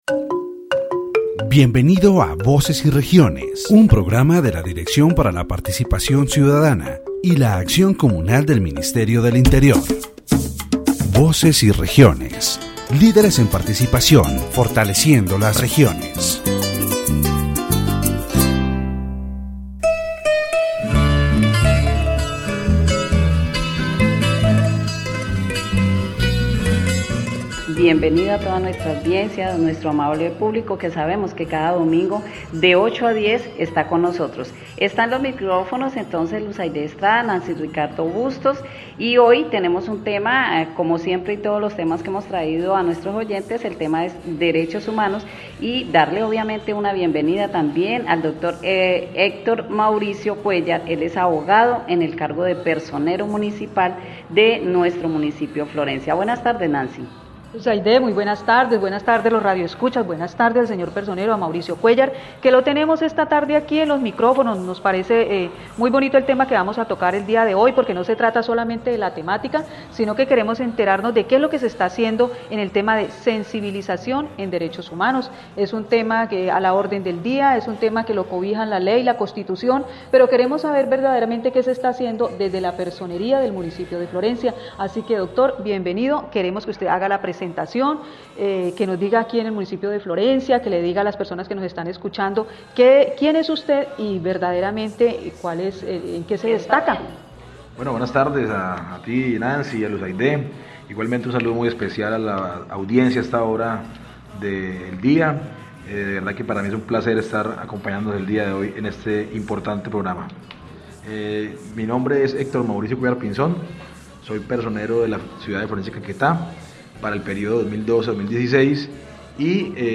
The radio program "Voces y Regiones" of the Ministry of the Interior is broadcast from Florencia, Caquetá, on station 98.1. In this episode, the topic of human rights and raising awareness about them is addressed. The special guest is Dr. Mauricio Cuellar, Municipal Representative of Florencia, who explains what human rights are, their importance and how they relate to the State. The rights of the people, the problems in local prisons, and the efforts of the Municipal Ombudsman to promote and protect human rights are discussed.